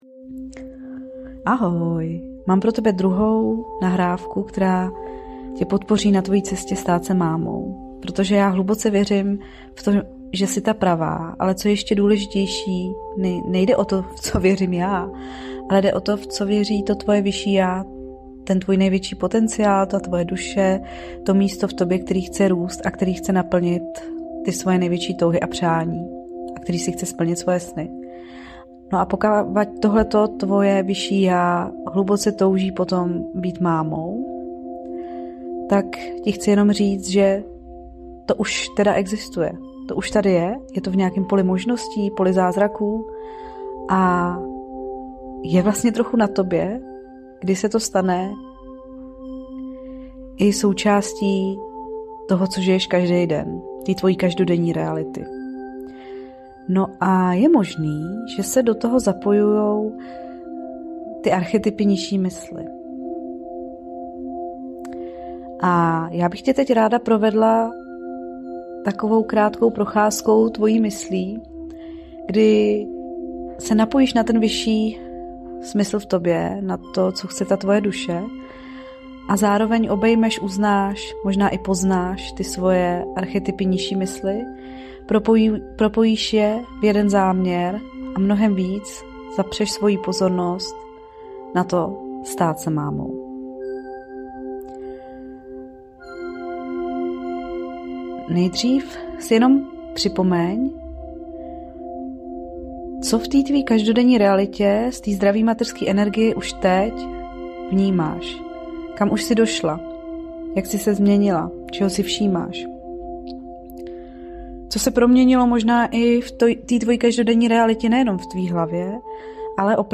Podpora - zvuková lázeň Ještě s námi nejsi ve skupině?
uzasna-podpora-zvukova-lazen.mp3